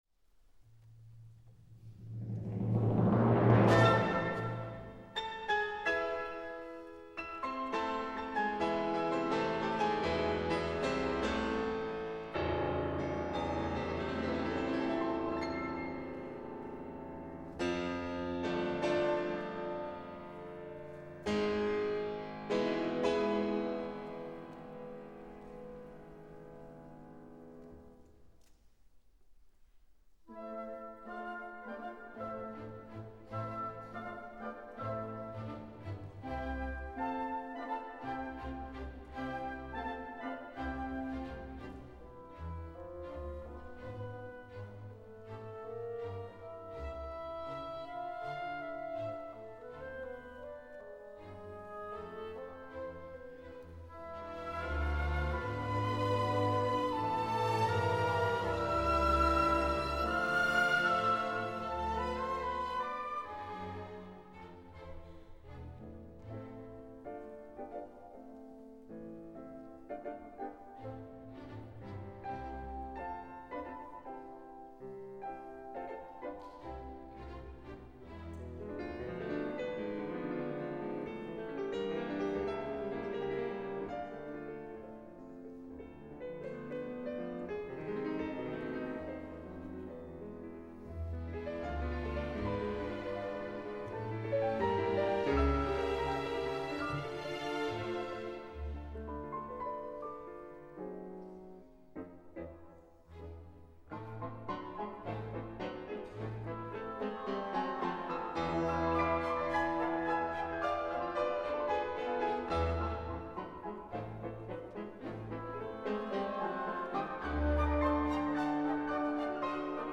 ΜΟΥΣΙΚΑ ΣΥΝΟΛΑ Ε.Ρ.Τ. – ΕΘΝΙΚΗ ΣΥΜΦΩΝΙΚΗ ΟΡΧΗΣΤΡΑ
Τρίτη 28 Νοεμβρίου 2023, Μέγαρο Μουσικής Αθηνών
Piano Concert in A minor
ΜΟΥΣΙΚΑ ΣΥΝΟΛΑ Ε.Ρ.Τ. – ΕΘΝΙΚΗ ΣΥΜΦΩΝΙΚΗ ΟΡΧΗΣΤΡΑ Τρίτη 28 Νοεμβρίου 2023, Μέγαρο Μουσικής Αθηνών Πρόγραμμα συναυλίας Grieg Edward: Piano Concert in A minor, Op 16 [31’] 1.